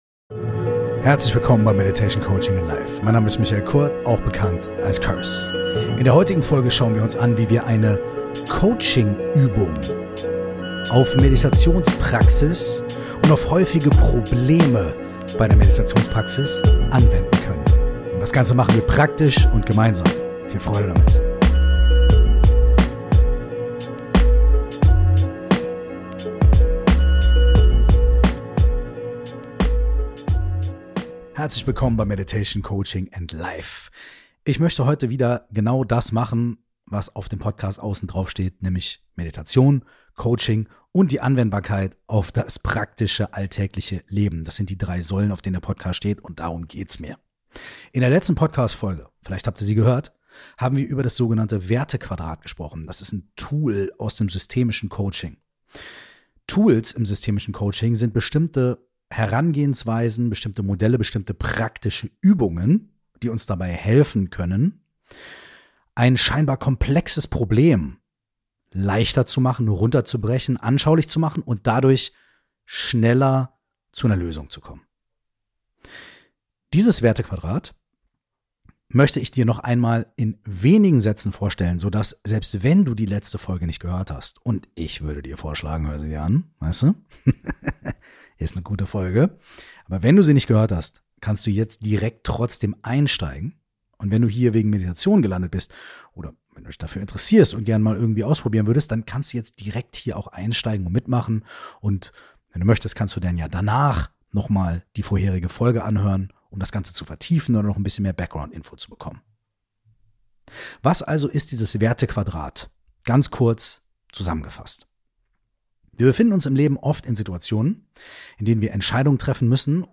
Wie in der letzten Podcast Folge bereits angekündigt, gibt es heute eine (weitere) Coaching Übung, unter anderem basierend auf der letzten Folge. Und auch wenn du noch nie meditiert hast, ist diese Übung hervorragend dafür geeignet einfach mitzumachen.